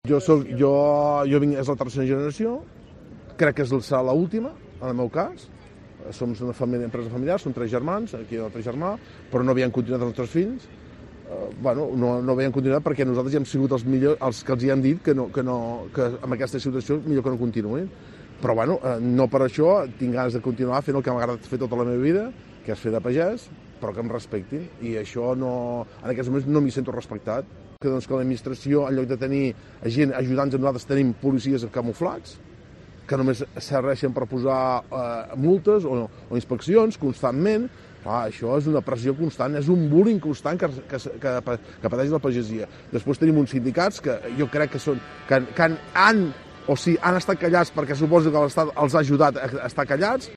agricultor de Girona, expresa el malestar del campo